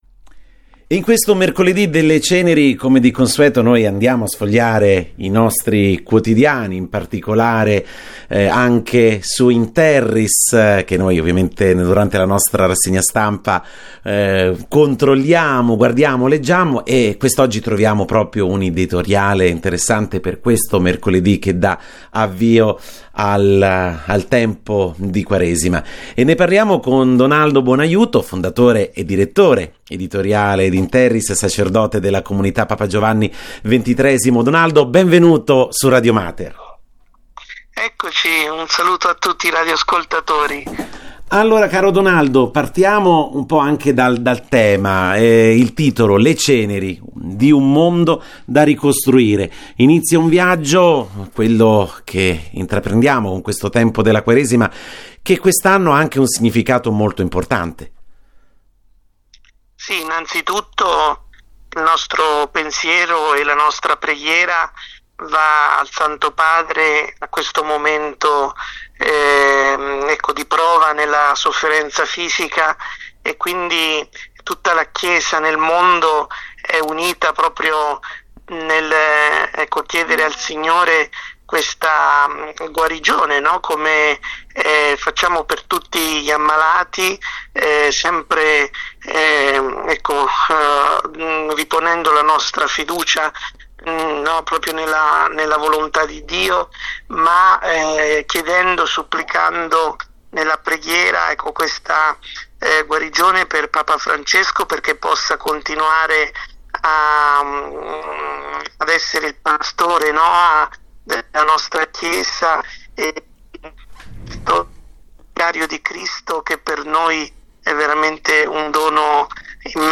In occasione del Mercoledì delle Ceneri, inizio della Quaresima, durante la rassegna stampa di Radio Mater Notizie è intervenuto